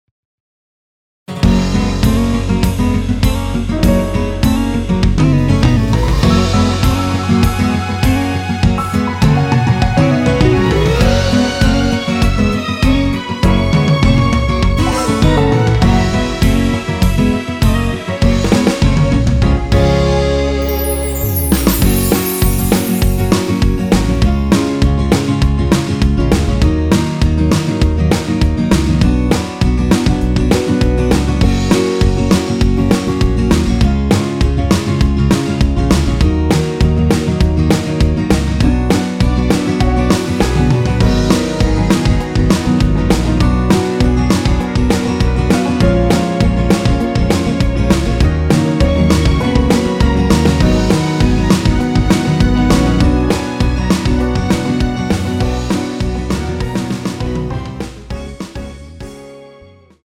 Gb
앞부분30초, 뒷부분30초씩 편집해서 올려 드리고 있습니다.
중간에 음이 끈어지고 다시 나오는 이유는